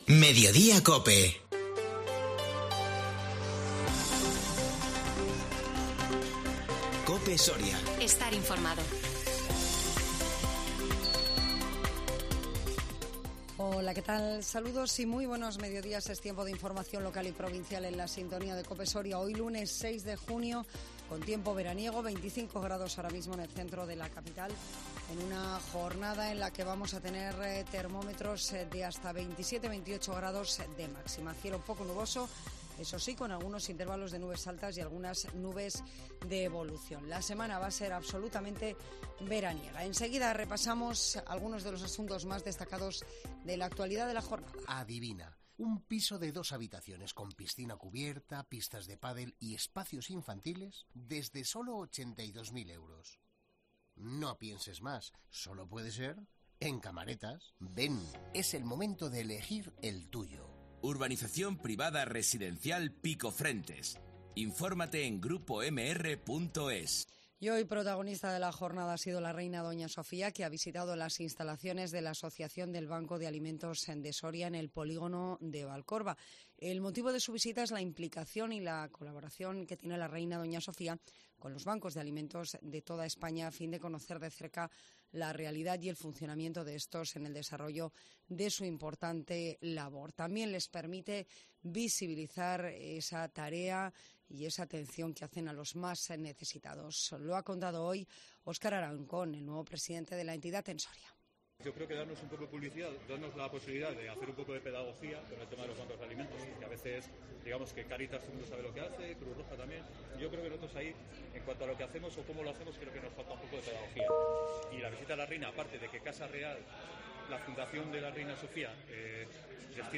INFORMATIVO MEDIODÍA COPE SORIA 6 JUNIO 2022